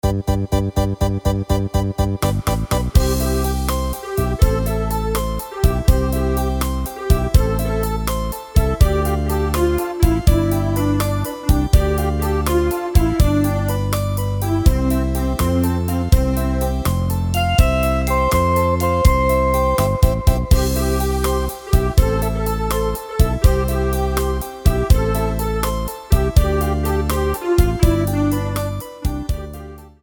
Karaoke
HUDEBNÍ PODKLADY V AUDIO A VIDEO SOUBORECH